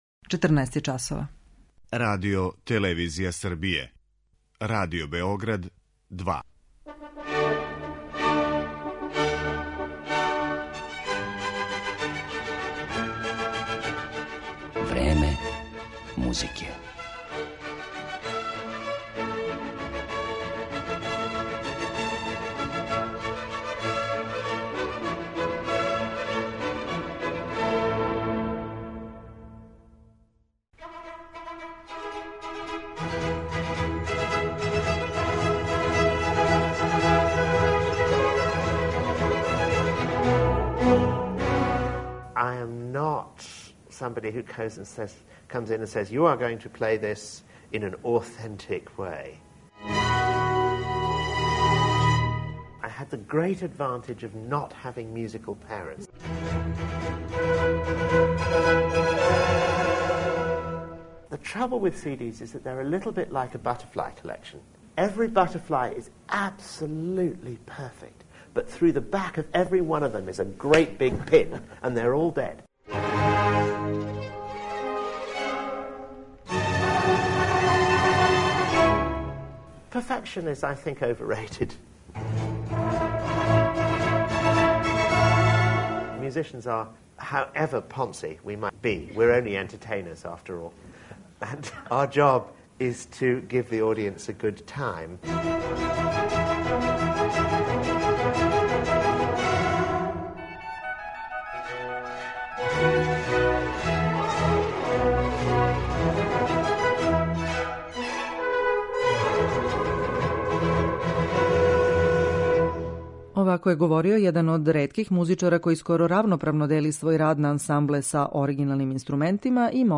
Данас ћемо га ипак представити у интерпретацијама литературе са којом се прославио и слушаћемо како тумачи дела барокних мајстора (Рамоа, Хендла, Баха, Матесона, Грауна и Корелија).